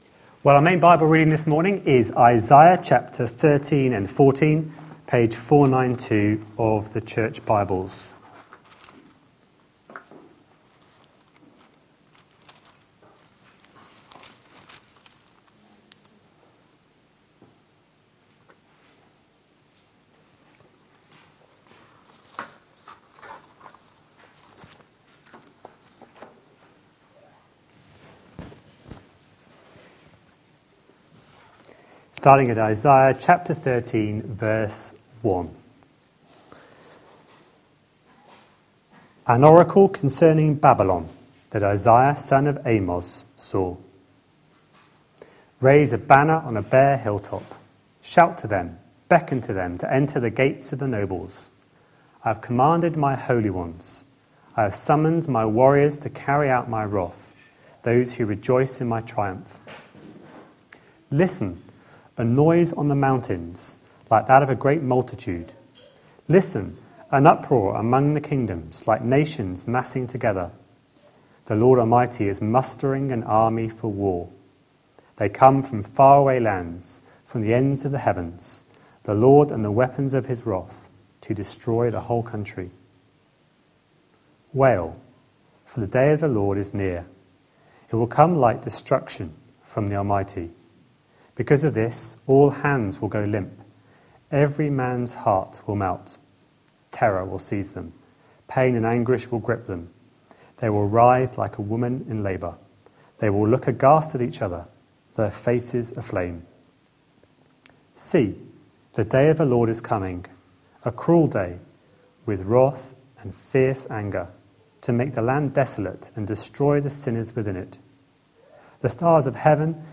A sermon preached on 12th April, 2015, as part of our No one compares series.